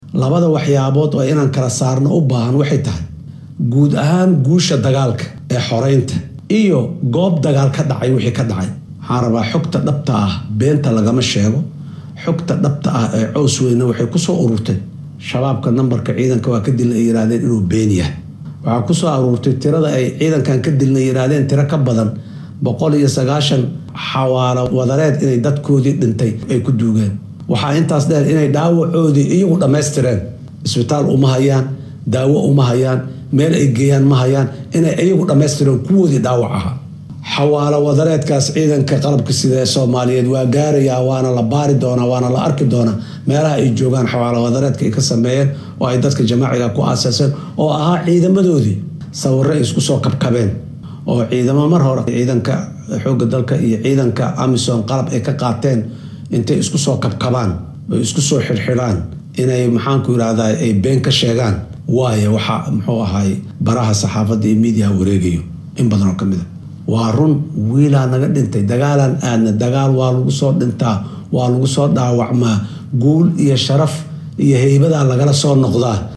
Madaxweynaha dalka Soomaaliya Xasan Sheekh Maxamuud oo xalay jeediyay hadal kooban oo laga siidaayay warbaahinta dowladda uuna kaga hadlayay dagaalka ka dhanka Al-Shabaab ayaa waxaa uu ku beeeniyay warar faafay oo sheegayay in khasaare xooggan ciidamada dowladda lagu gaarsiiyay dagaal dhawaan ku dhexmaray iyaga iyo ururkan deegaanka Cowsweyne ee gobolka Galgaduud. Waxaa uu tilmaamay in muuqaallada iyo wararka baraha bulshada lagu baahiyay ee ah in askar badan dagaalkaasi lagu dilay hubna looga qabsaday ciidamada dowladda uusan sax ahayn.